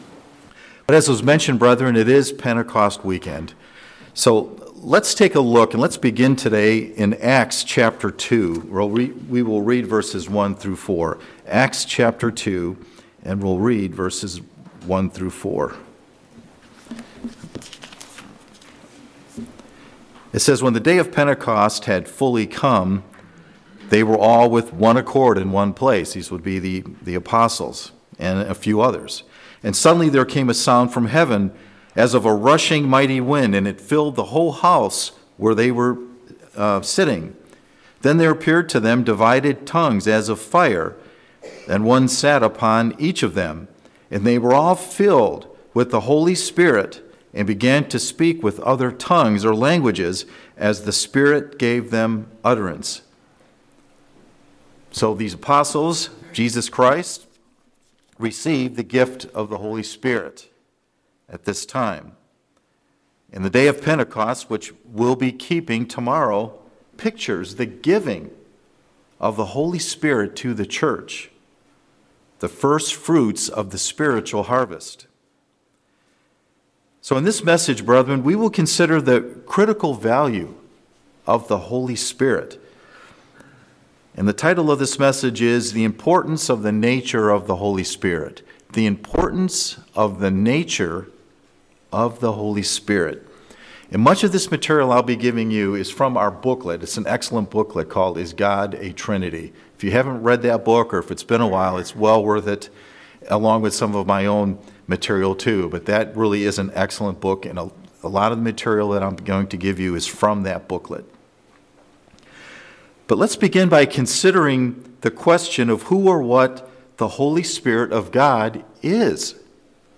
This sermon considers the critical value of the Holy Spirit and will look at who or what the Holy Spirit is.